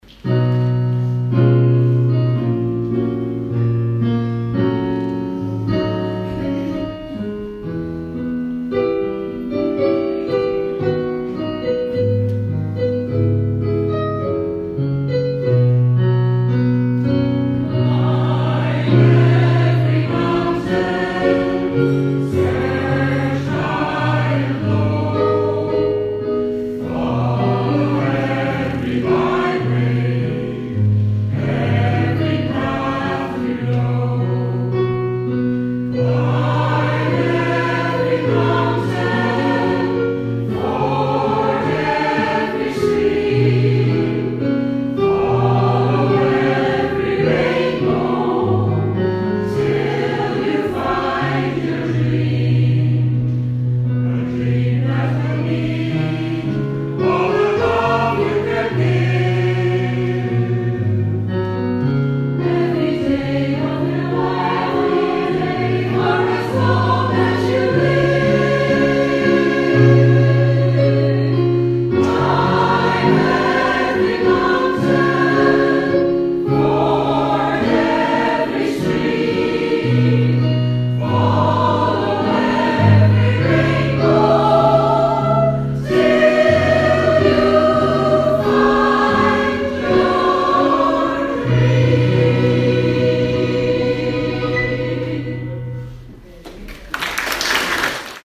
A great crowd turned out for the Guild social coffee evening with the Community Choir entertaining us with  a selection of popular songs.
Music from the shows and favourite films, with some scottish extras brought back memories for many of the audience, with a few singing along to those golden favourites.